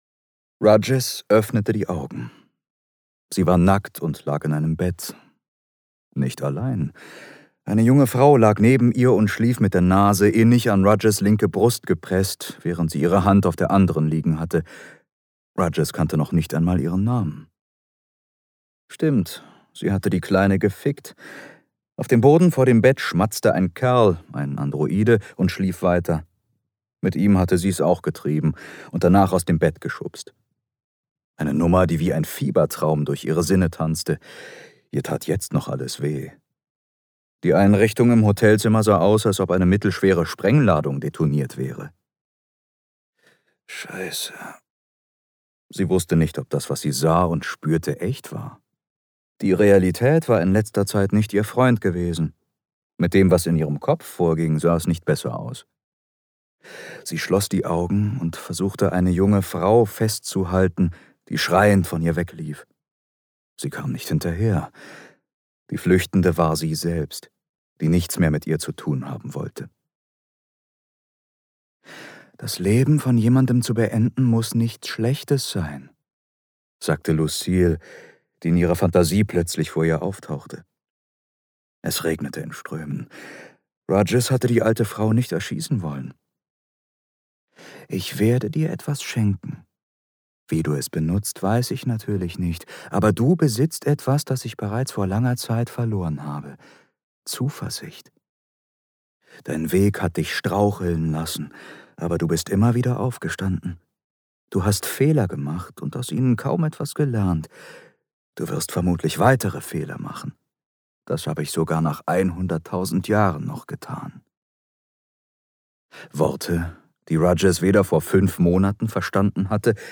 Themenwelt Literatur Fantasy / Science Fiction Science Fiction